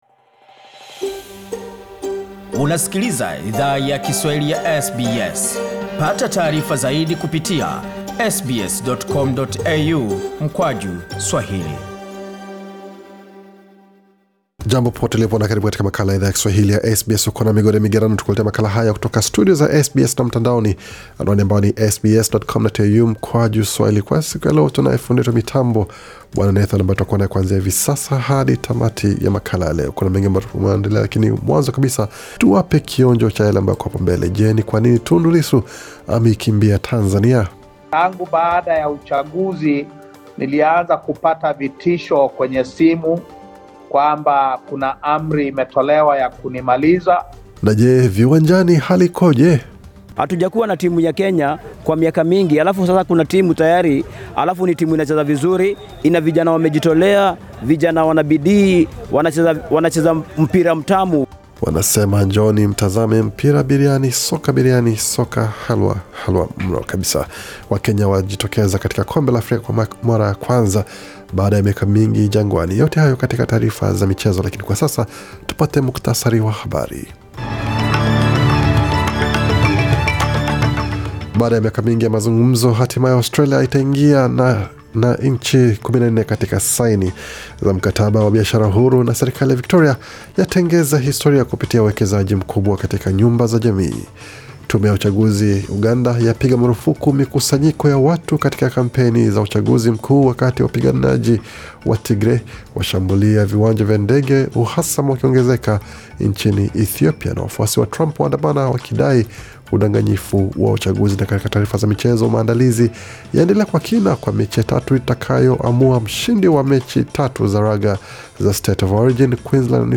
Taarifa ya habari 15 Novemba 2020